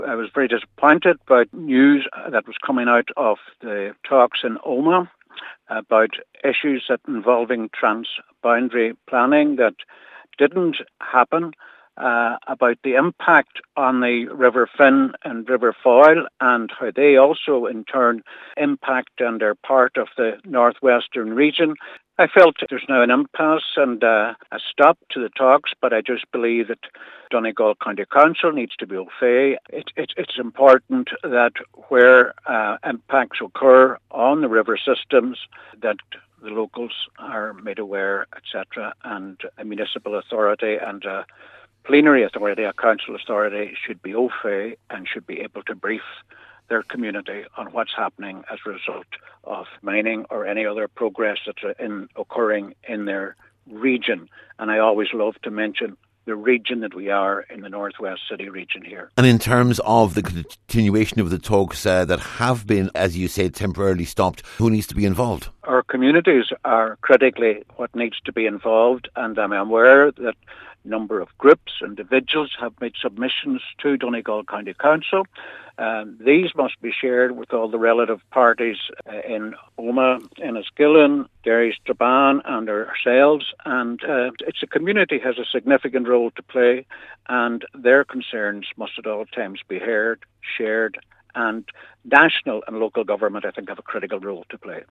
Cllr Albert Doherty told a meeting of Inishowen Municipal District this is too important an issue to ignore……..